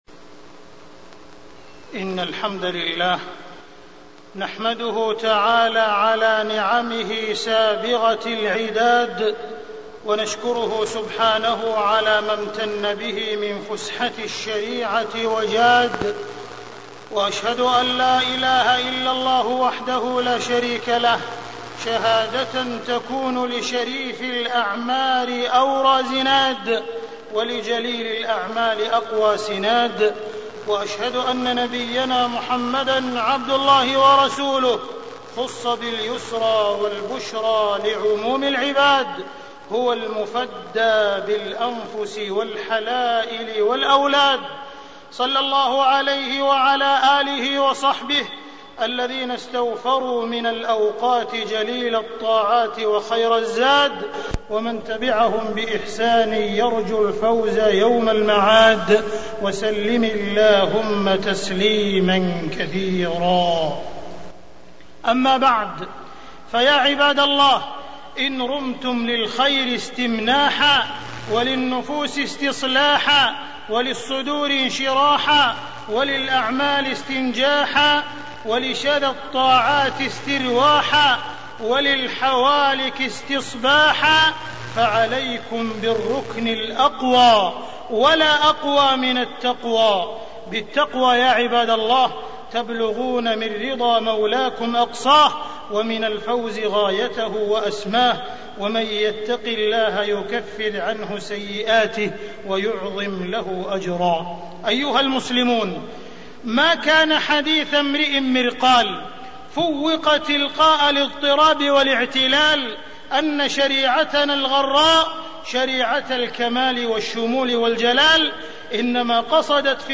تاريخ النشر ٢٠ جمادى الأولى ١٤٢٧ هـ المكان: المسجد الحرام الشيخ: معالي الشيخ أ.د. عبدالرحمن بن عبدالعزيز السديس معالي الشيخ أ.د. عبدالرحمن بن عبدالعزيز السديس وصايا لأجازة الصيف